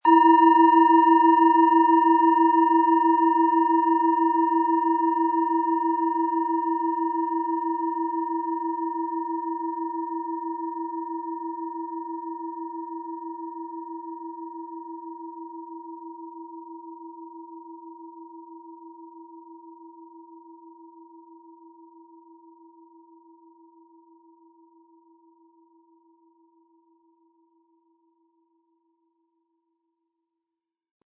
Tibetische Bauch- und Herz-Klangschale, Ø 11,8 cm, 180-260 Gramm, mit Klöppel
Um den Original-Klang genau dieser Schale zu hören, lassen Sie bitte den hinterlegten Sound abspielen.
Lieferung inklusive passendem Klöppel, der gut zur Klangschale passt und diese sehr schön und wohlklingend ertönen lässt.
HerstellungIn Handarbeit getrieben
MaterialBronze